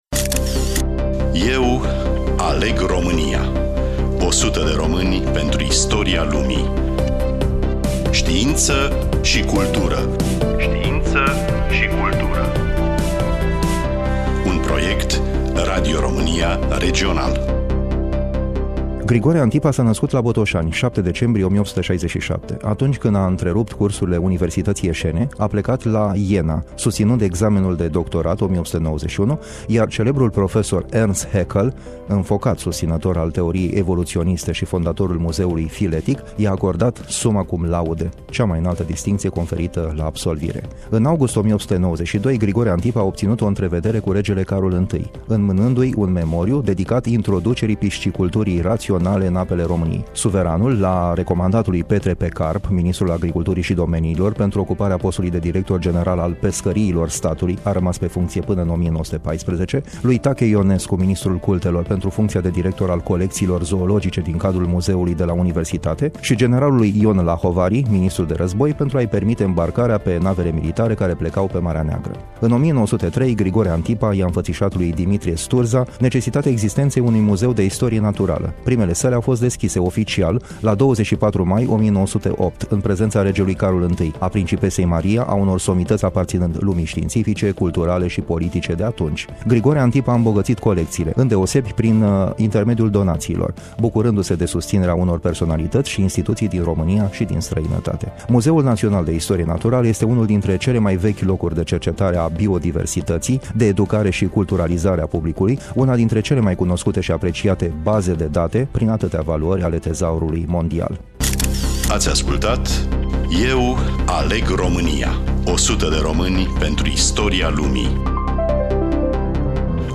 Studioul: Radio România Iași